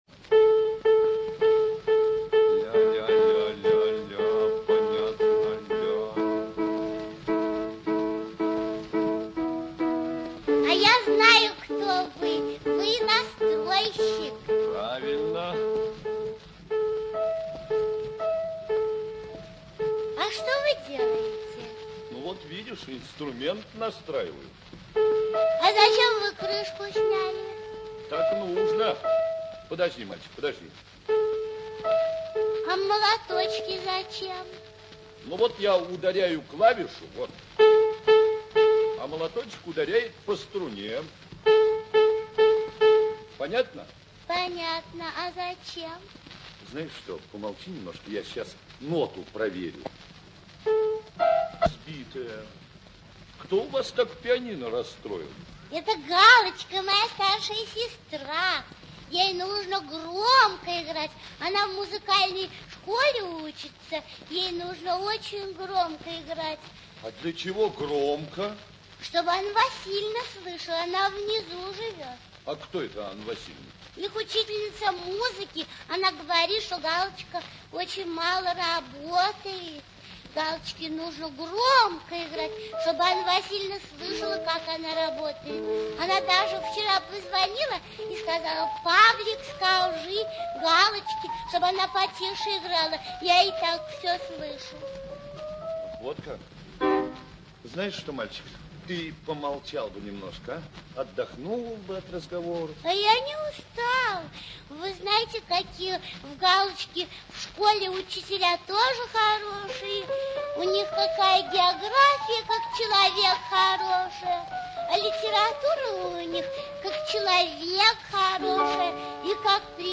Р. Зеленая и Л. Пирогов
rina-zelnaya-i-l.pirogov---razgovor-s-nastroyschikom.mp3